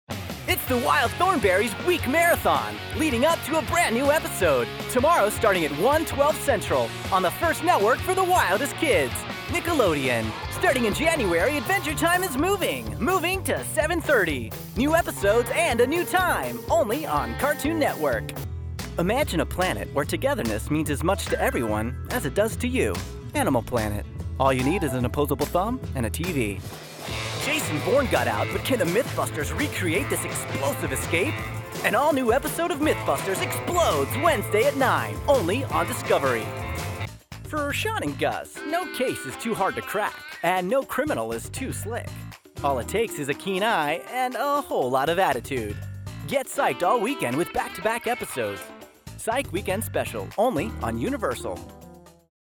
Promo Demo
Young Adult
Middle Aged